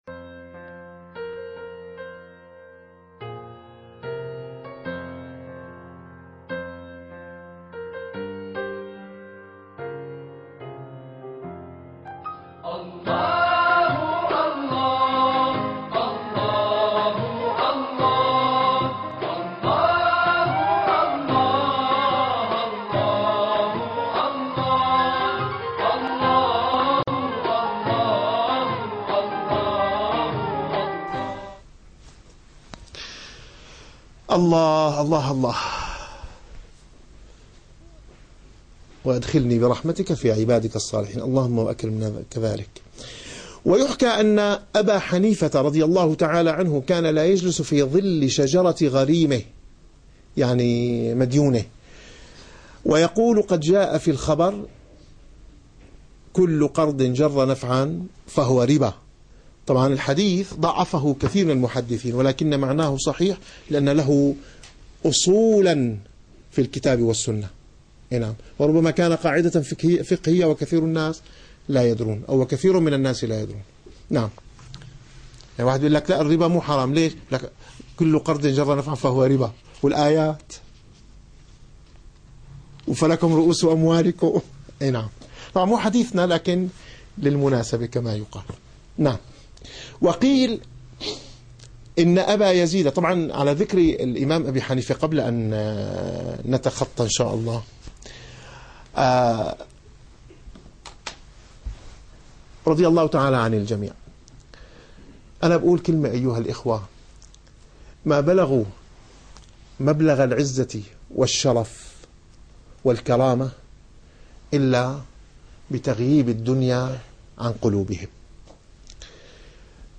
- الدروس العلمية - الرسالة القشيرية - الرسالة القشيرية / الدرس الثالث والخمسون.